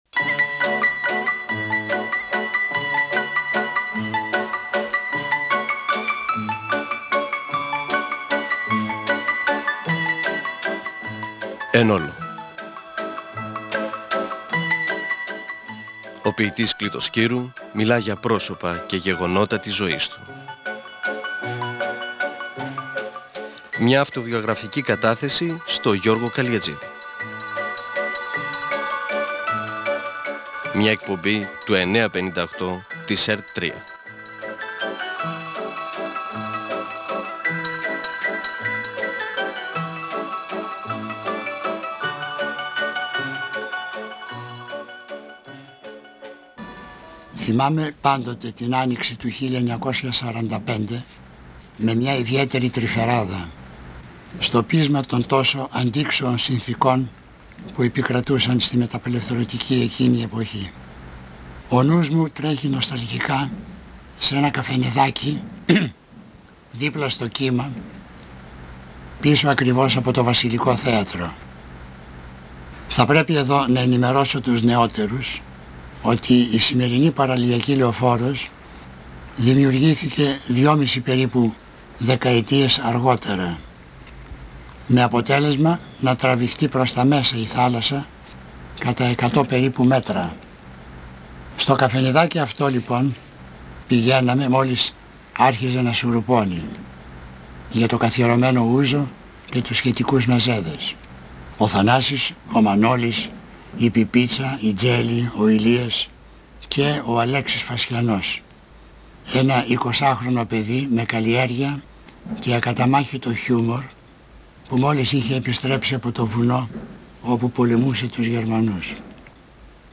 συνομιλία-συνέντευξη
Μιλά για την ποίηση και τη μετάφραση των ποιημάτων τού Φ.Γ. Λόρκα. Διαβάζει το ποίημα του «Τα τείχη».